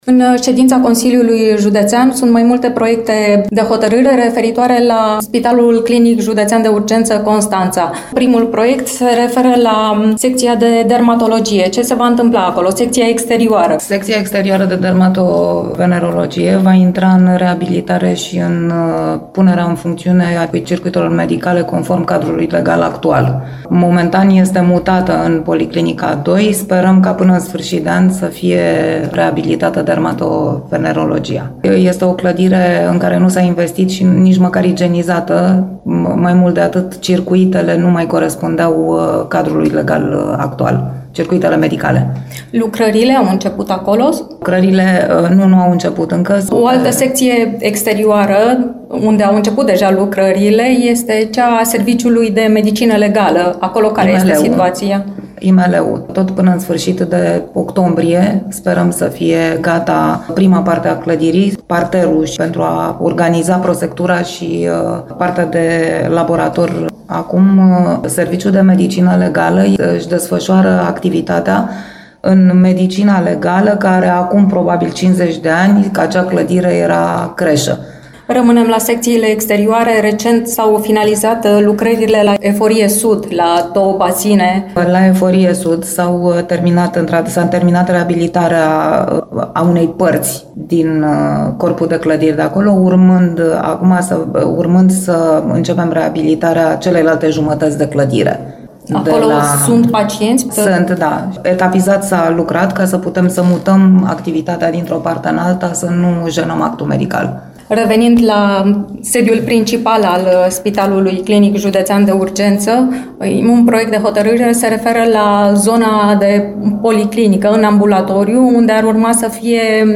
În ce fază este procedura de aprobare a personalului suplimentar, dar și în ce stadiu sunt proiectele referitoare la Spitalul de Urgență Constanța, unele aprobate în ultima ședință a Consiliului Județean, aflăm din interviul următor: